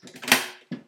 toolbox.ogg